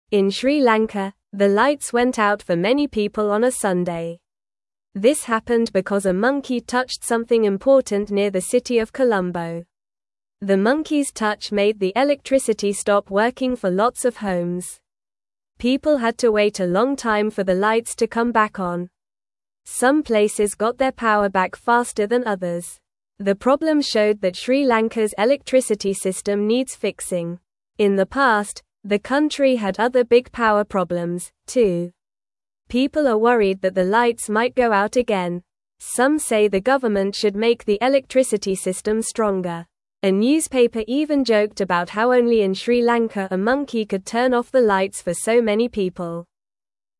Normal
English-Newsroom-Beginner-NORMAL-Reading-Monkey-Turns-Off-Lights-in-Sri-Lanka-for-Everyone.mp3